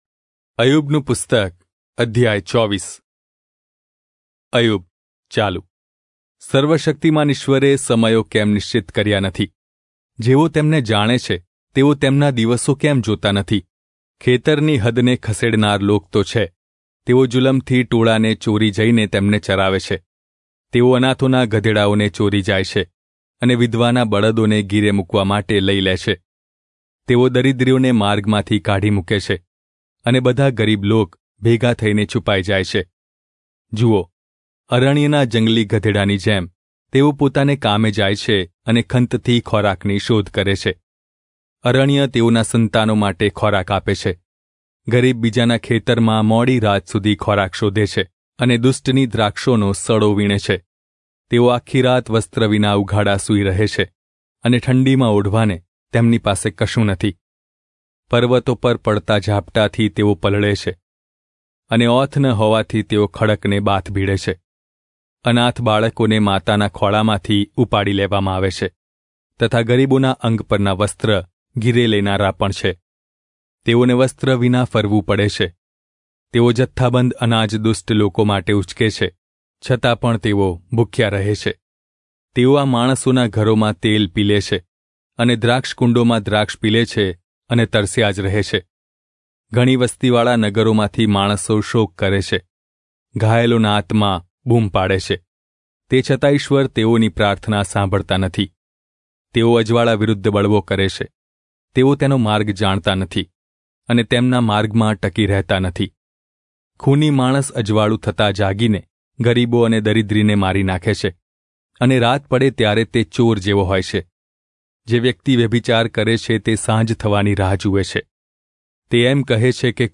Gujarati Audio Bible - Job 40 in Irvgu bible version